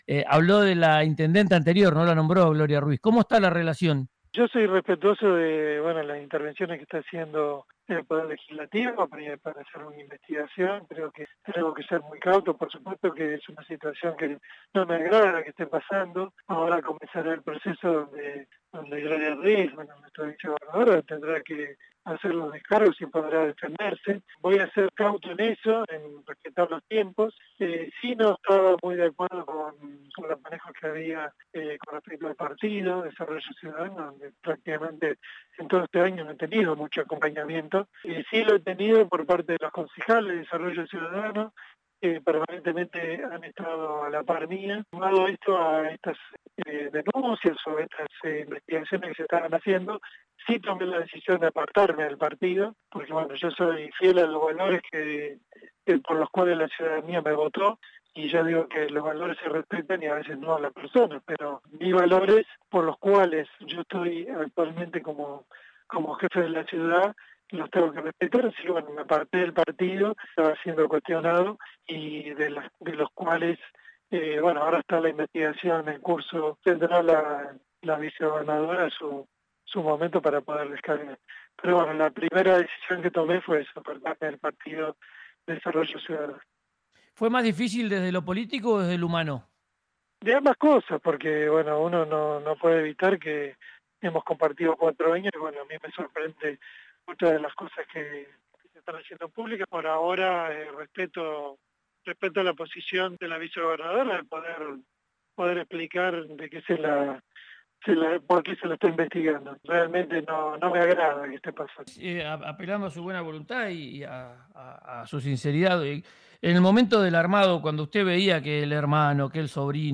Escuchá al intendente de Plottier, Luis Bertolini, en RIO NEGRO RADIO